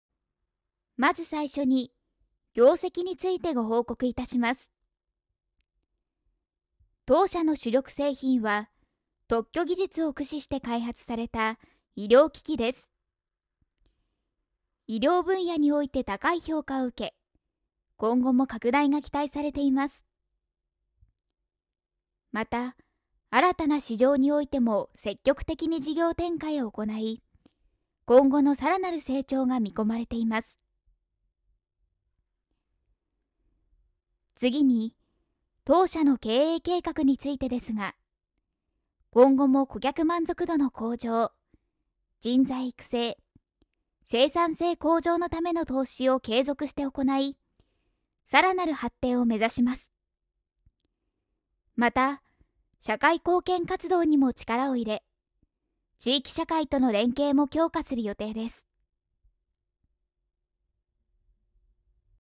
丁寧・高品質・リーズナブルなプロの女性ナレーターによるナレーション収録
ヒアリングテスト
番組の宣伝